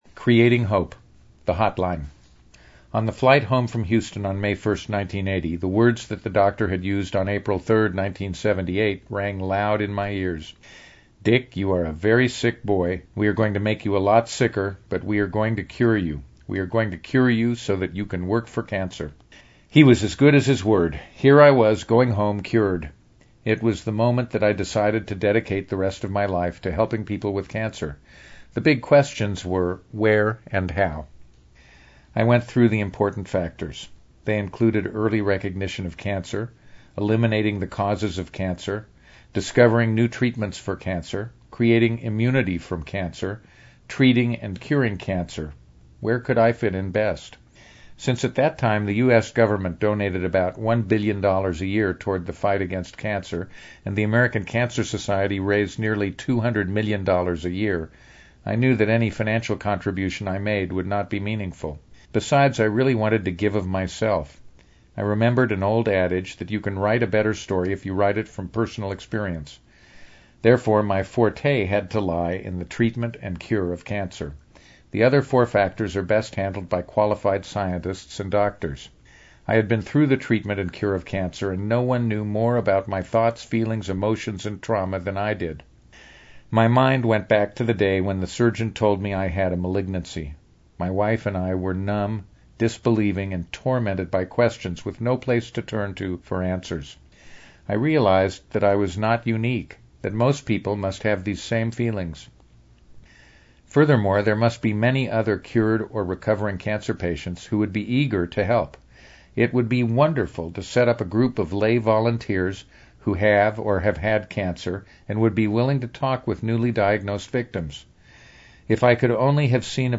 Audio Book – Cancer …theres hope – Richard & Annette Bloch Family Foundation